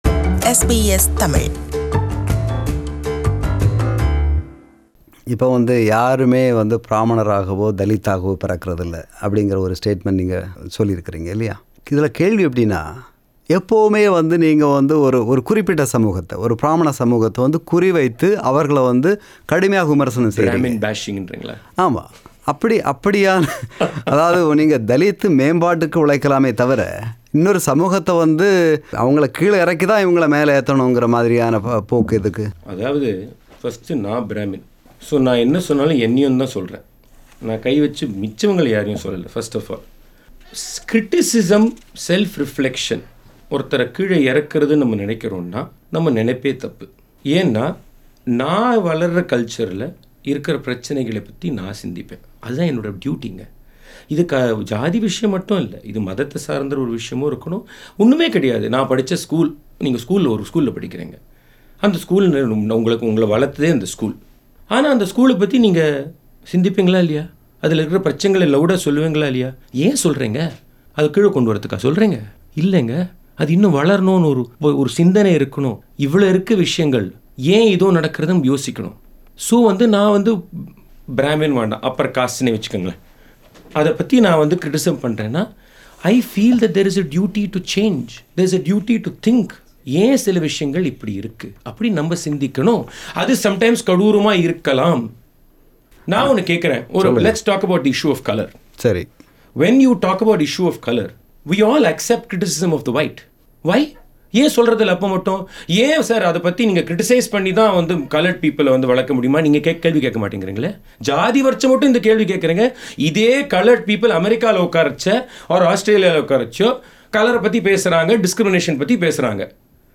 Interview with T.M. Krishna – Part 2